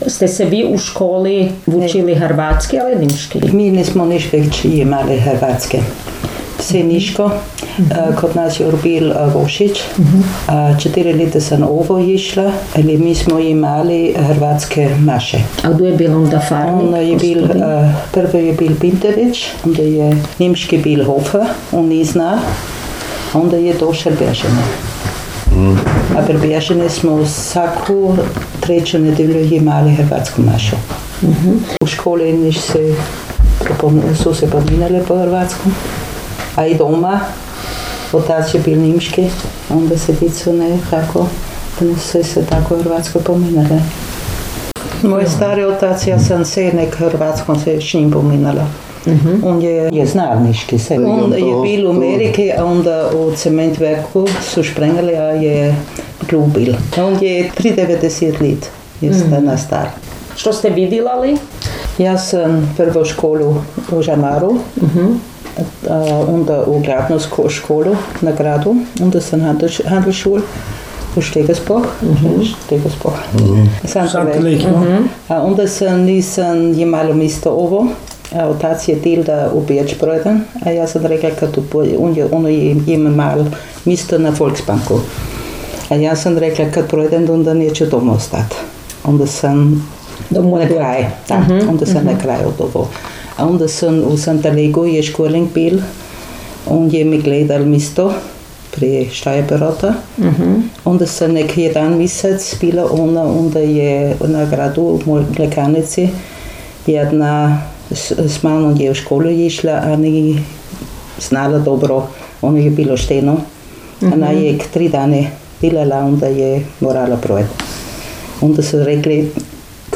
jezik naš, jezik naš gh dijalekti
Žamar – Govor
Reinersdorf im Burgenland
47_Zamar_govor.mp3